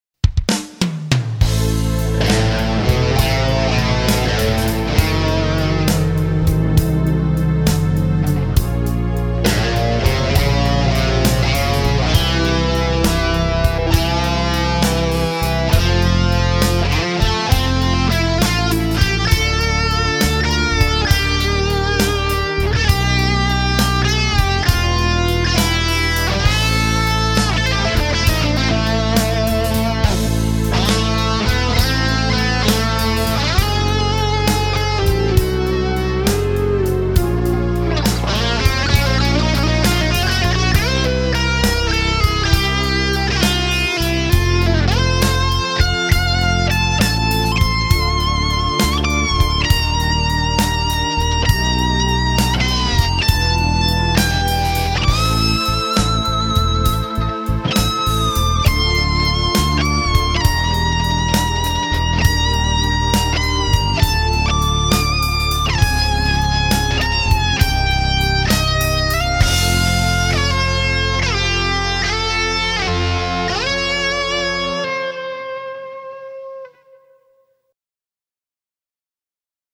Excerpts of Recorded Guitar Work
Electric
Heavy Rock/Metal/Blues Rock